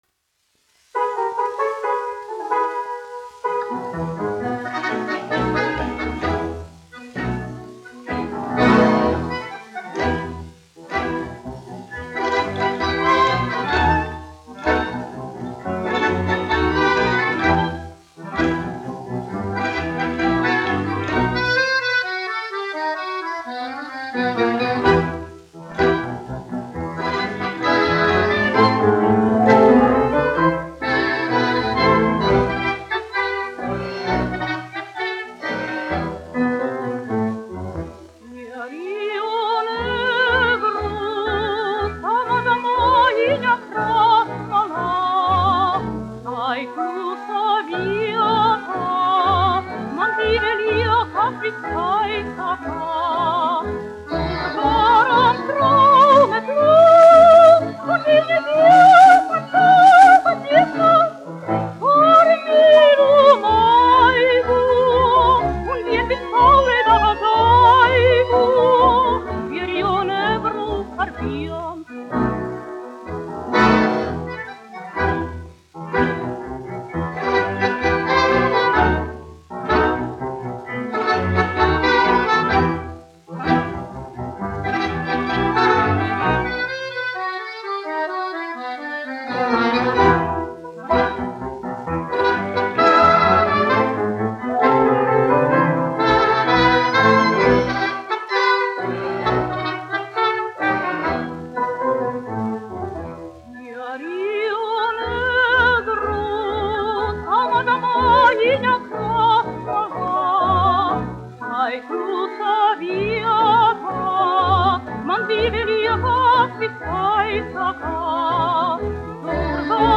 1 skpl. : analogs, 78 apgr/min, mono ; 25 cm
Mūzikli--Fragmenti